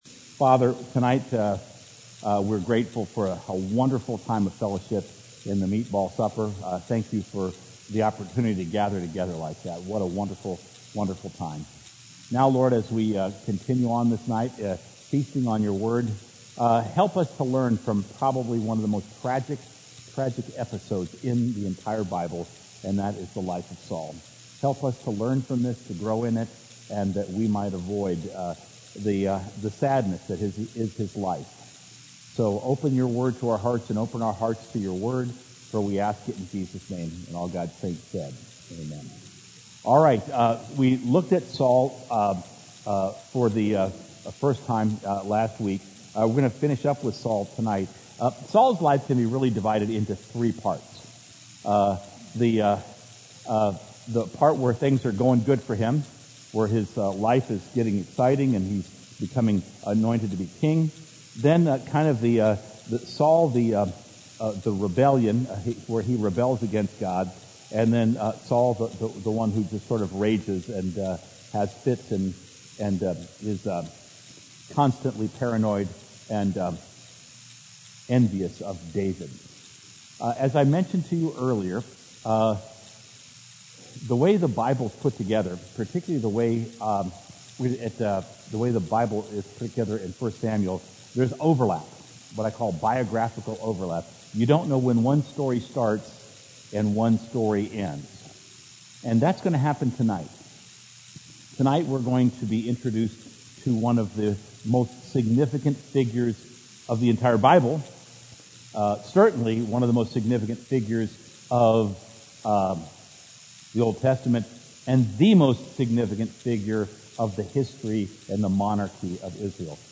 Experience the Word Bible Study